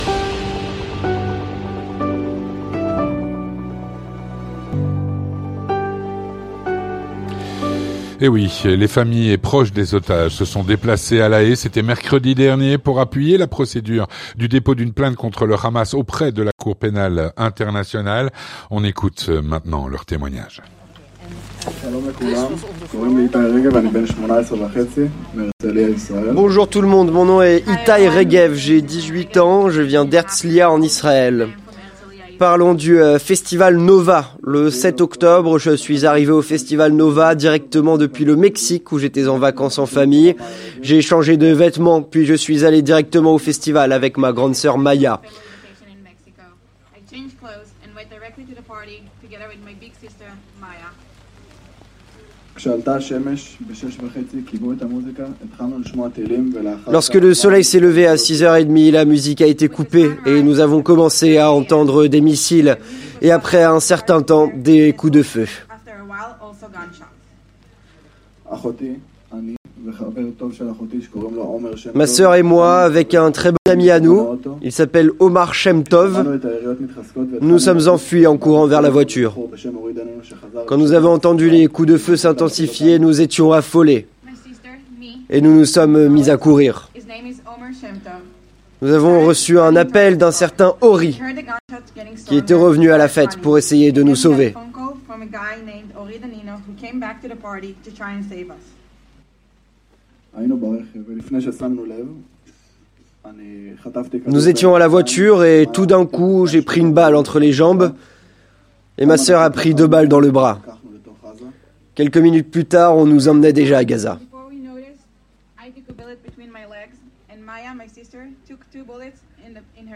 Témoignage - Les familles et proches des otages se sont déplacées à la Haye, mercredi dernier, auprès de la Cour de Justice Internationale.
Écoutons leurs témoignages.